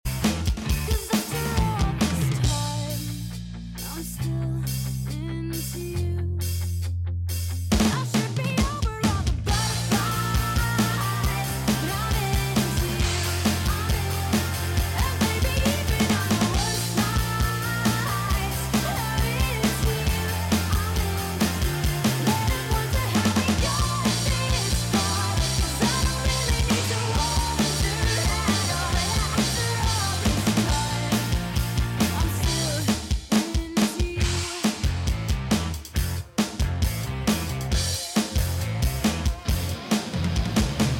drum cover